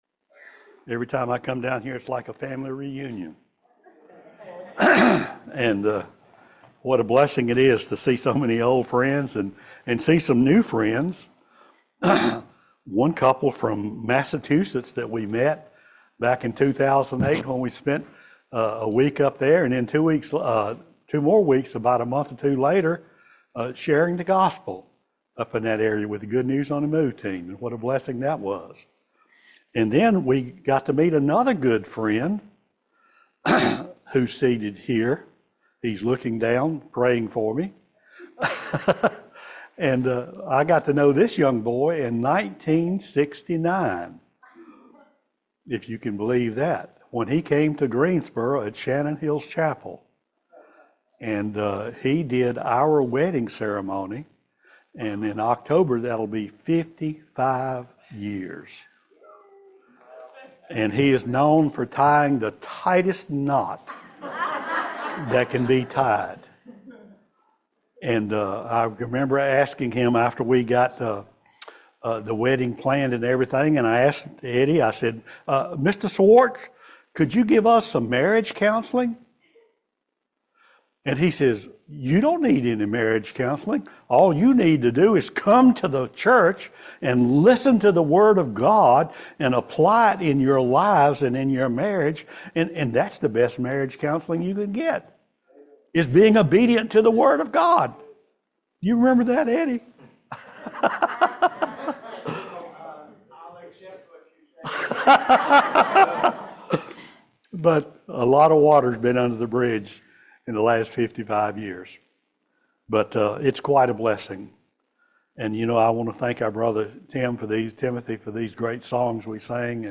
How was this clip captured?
Isaiah 40 Service Type: Family Bible Hour Those who wait on the Lord shall renew their strength.